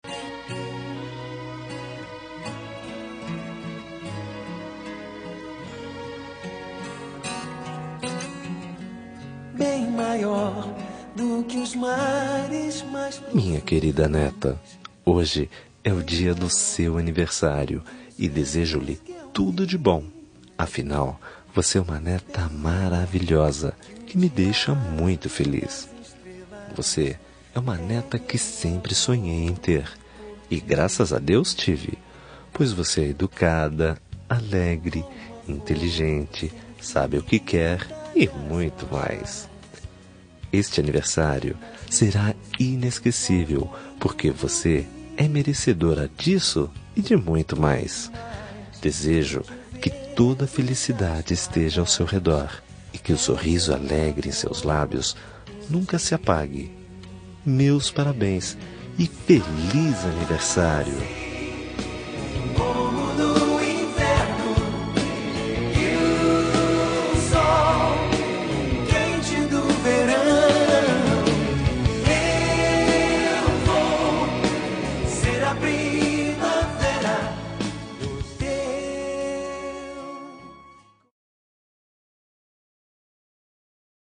Aniversário de Neta – Voz Masculina – Cód: 131032